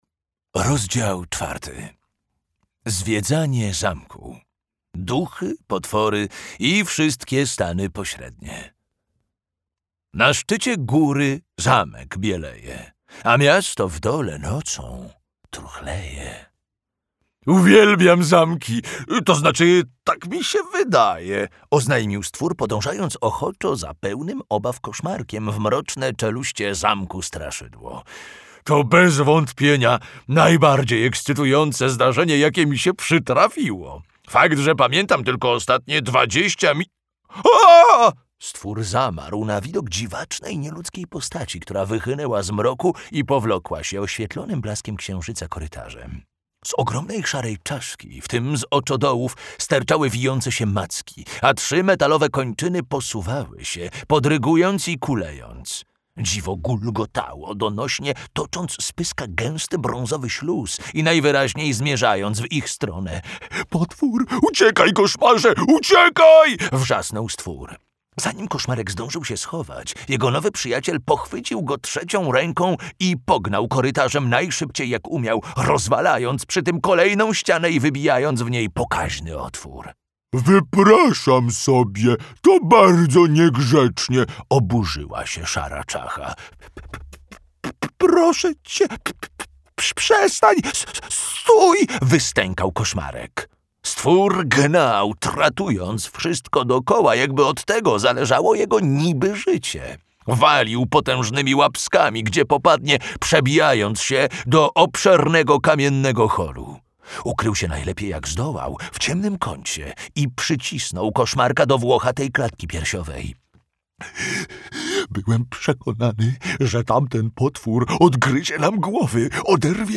Koszmarek - Guy Bass - audiobook + książka